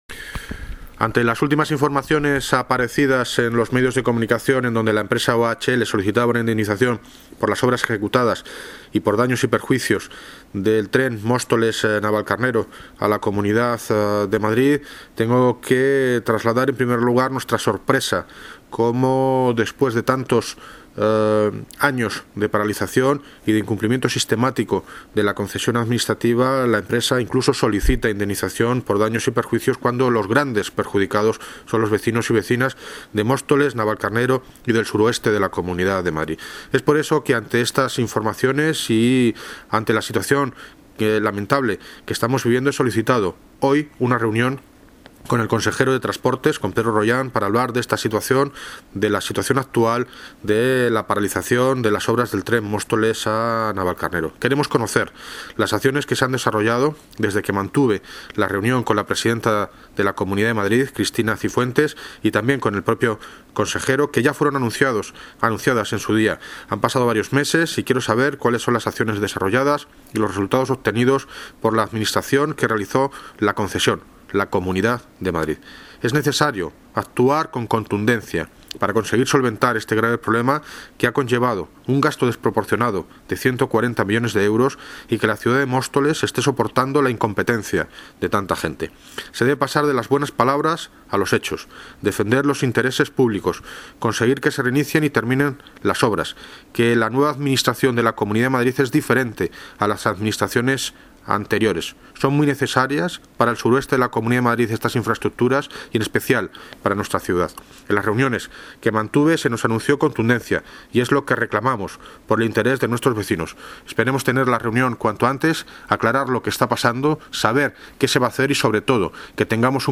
Audio - David Lucas (Alcalde de Móstoles) Sobre Tren